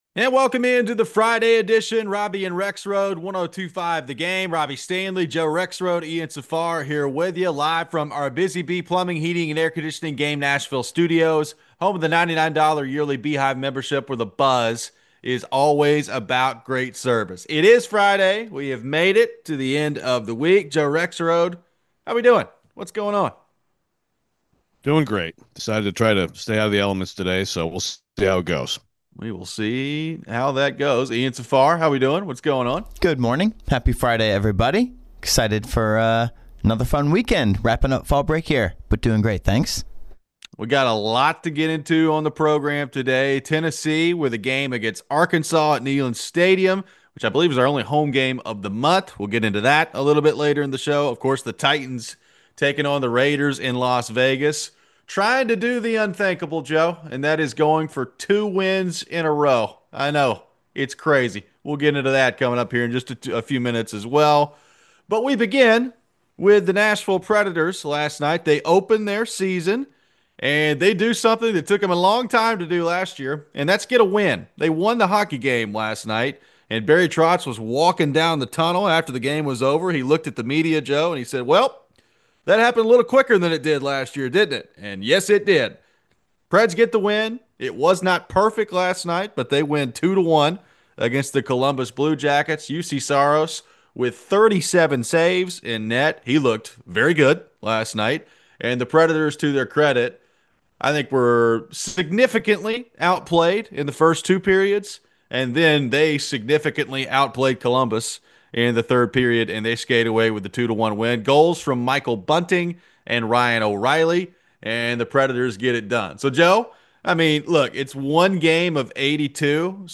We take your phones. Is it fair to expect the best game of the season from Cam Ward?